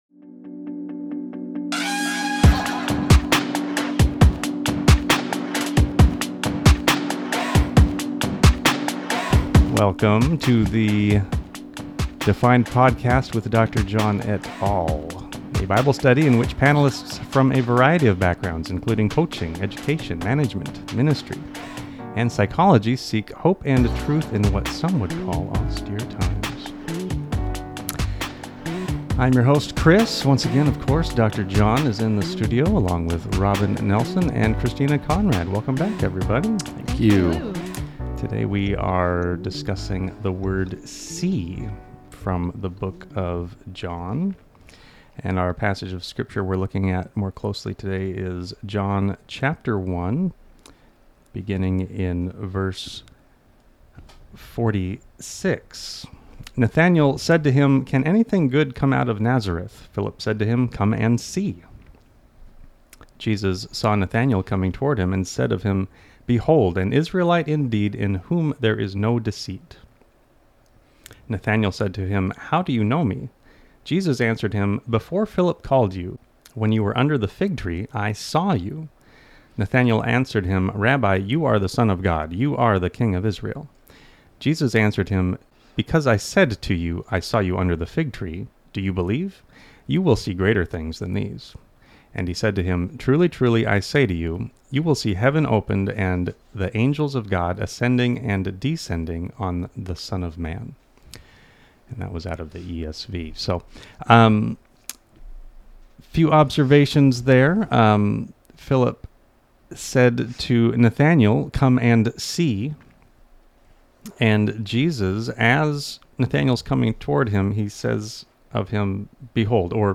S1 E10 | "See" | Bible Study John 1:46-51